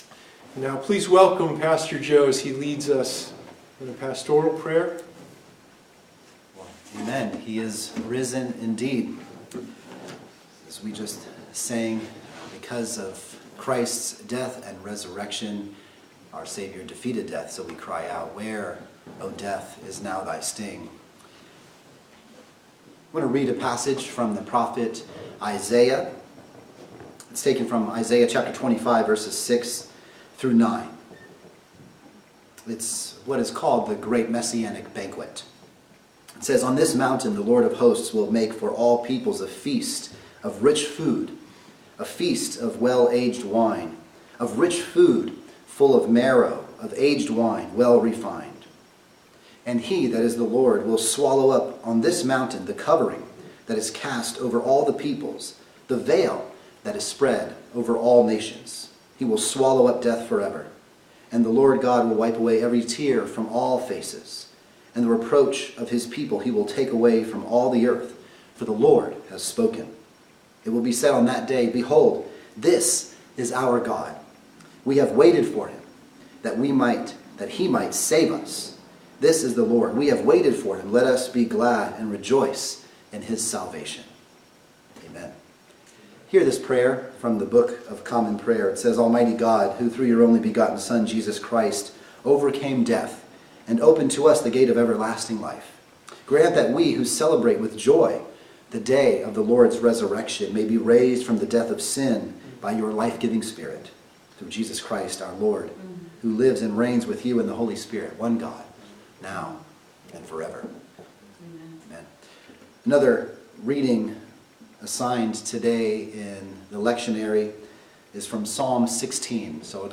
Palm Sunday message